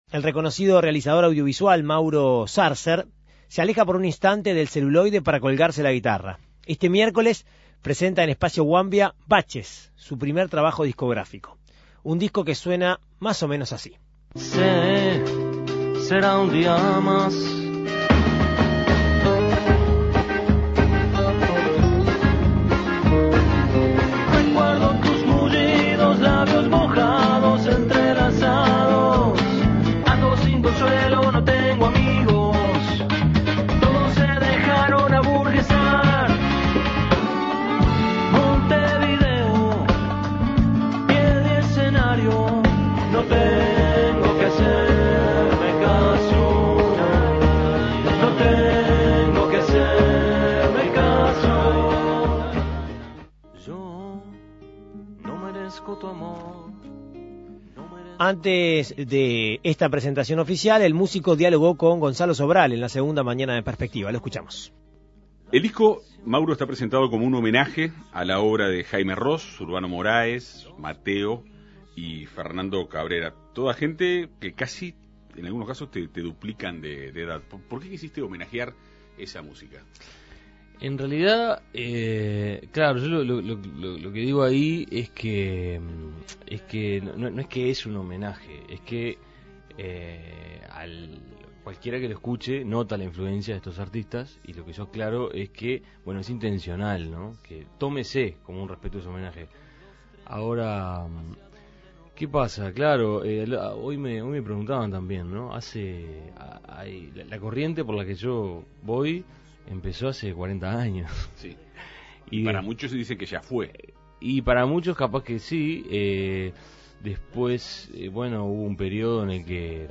Antes de esta presentación oficial, el músico dialogó con En Perspectiva Segunda Mañana.